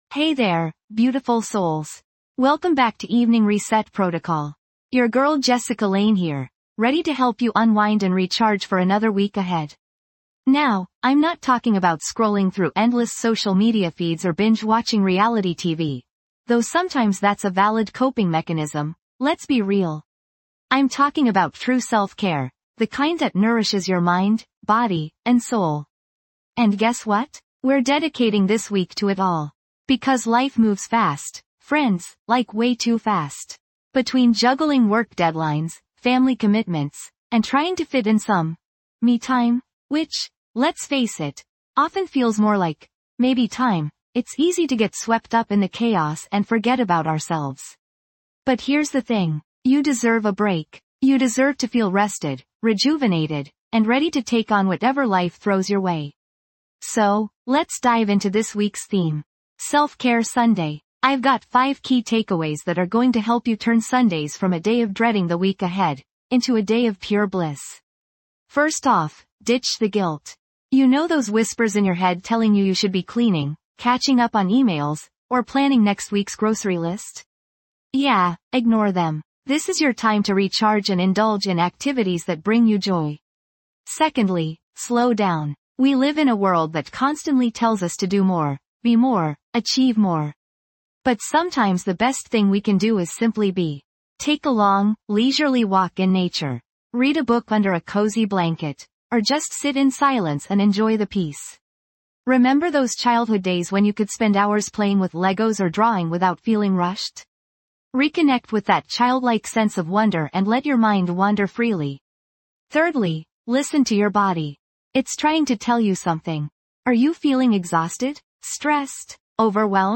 Through soothing soundscapes and carefully crafted affirmations, we'll help you release the day's burdens, rewire negative thought patterns, and cultivate a sense of inner peace.